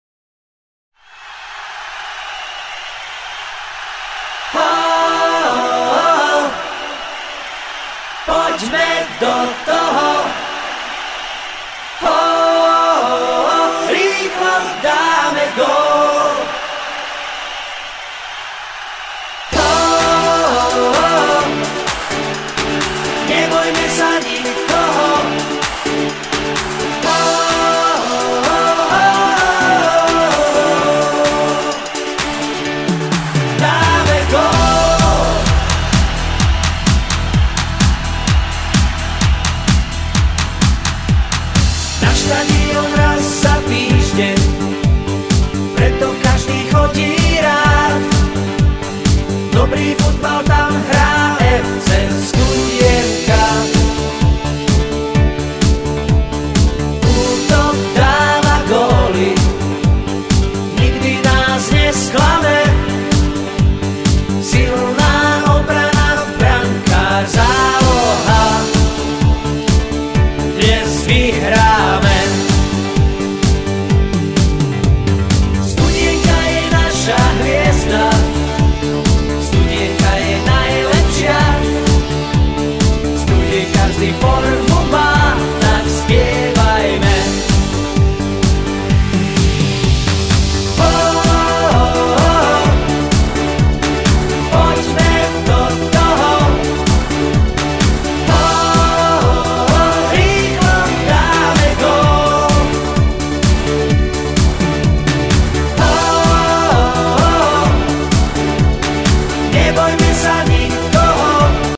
Od jesene 2001 maj� futbalisti nov� hymnu